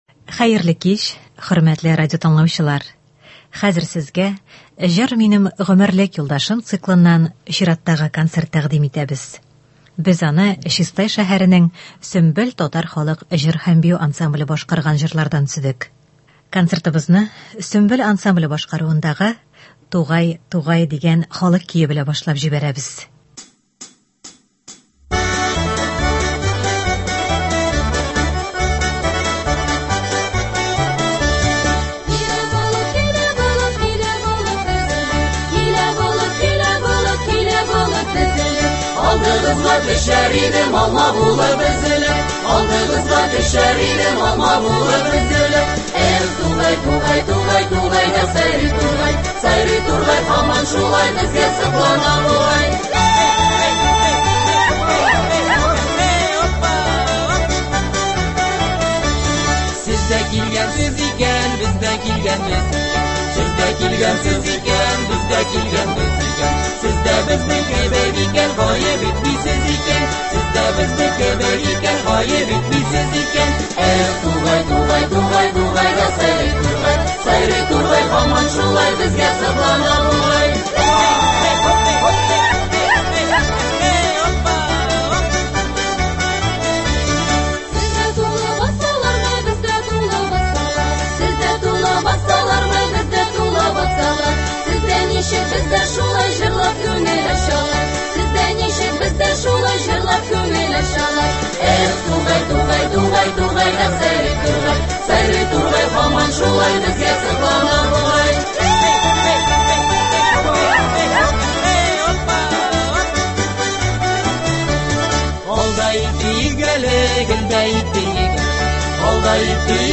Үзешчән башкаручылар чыгышы.
Концерт (06.05.24)